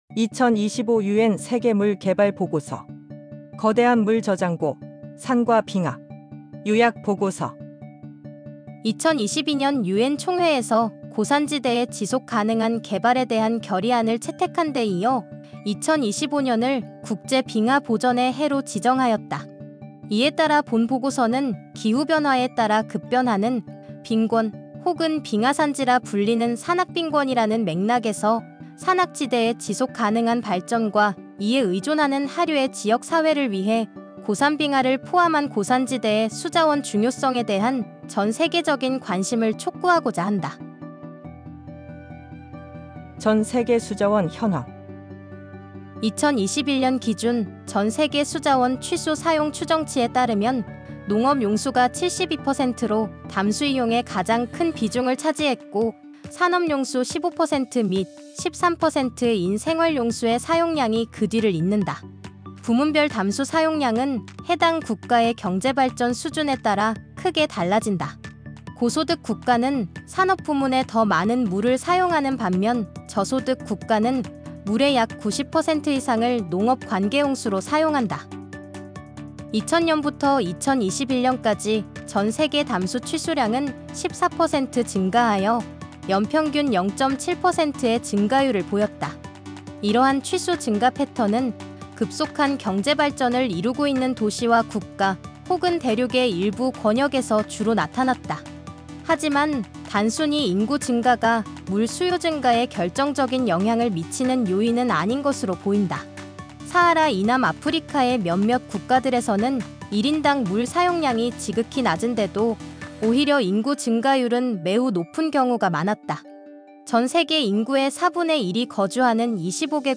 WWDR_2025_KR_Audiobook.mp3 WWDR_2025_KR_Summary.pdf